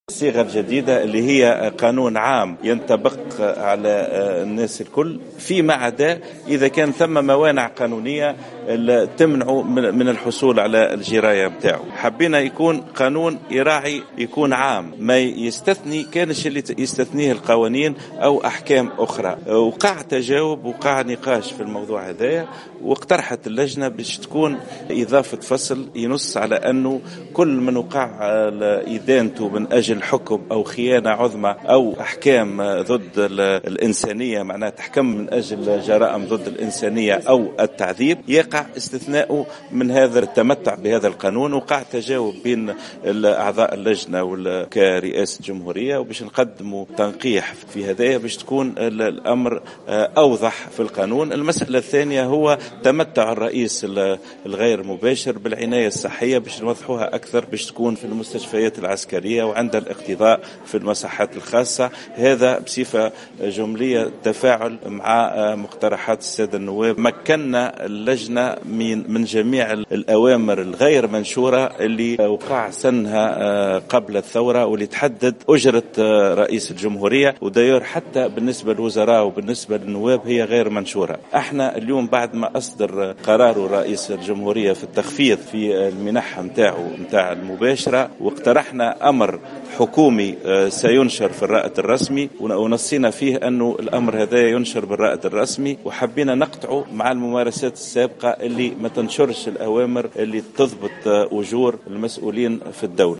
وأفاد رضا بلحاج في تصريح للصحفيين، عقب الجلسة، بأن أعضاء اللجنة أثاروا عدة تساؤلات، من بينها تمتع الرئيس الأسبق زين العابدين بن علي بامتيازات ما بعد مغادرة منصب رئيس الجمهورية، وفقا لمقتضيات قانون سنة 2005 الذي لا يزال ساري المفعول، إلا أنه تم التوافق بعد نقاش طويل صلب اللجنة على أن الصيغة الجديدة التي ستكون في شكل قانون عام ينطبق على الجميع يمكن تضمينه فصولا تستثني كل من تتعلق به موانع قانونية من التمتع بجراية ما بعد انتهاء المهام.